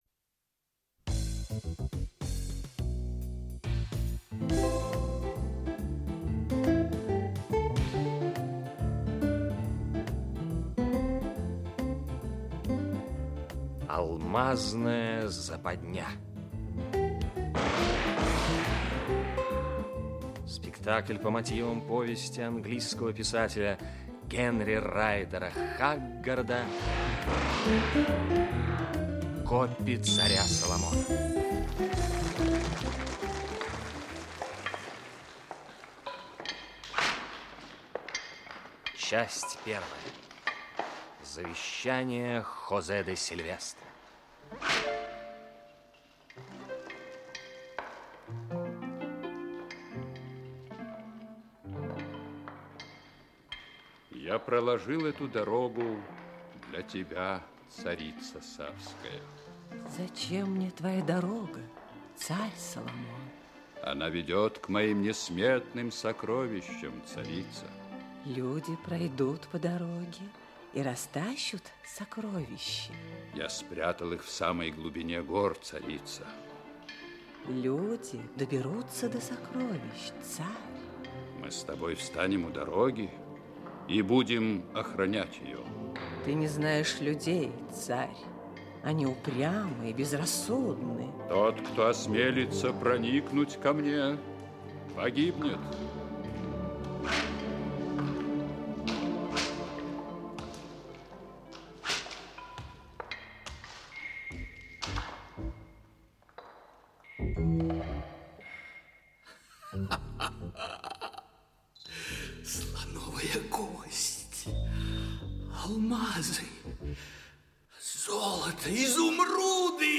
Радиоспектакль по мотивам романа “Копи царя Соломона”.